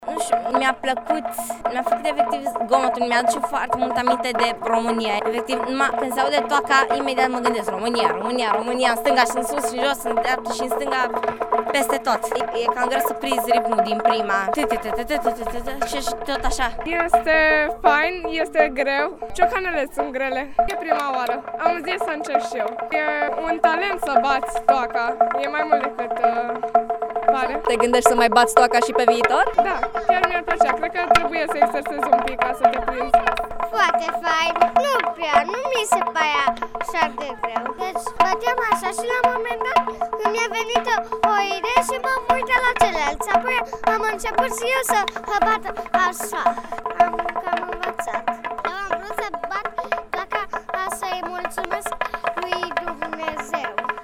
Voxuri-copii.mp3